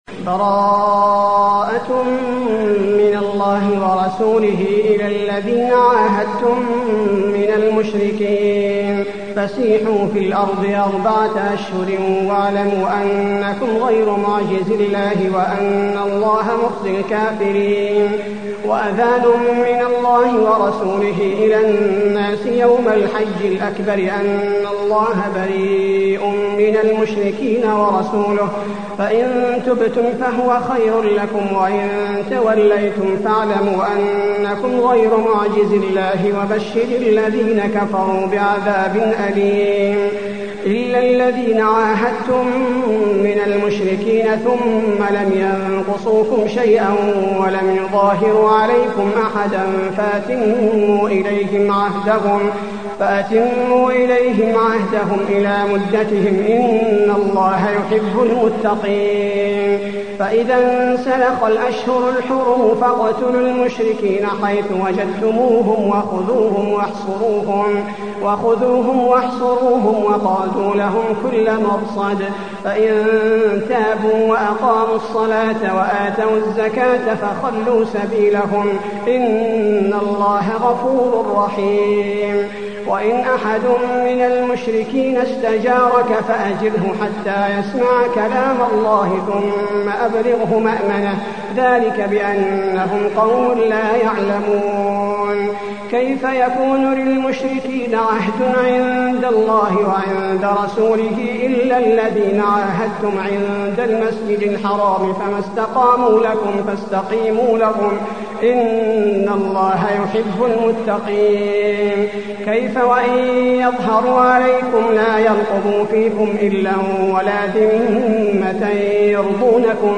المكان: المسجد النبوي التوبة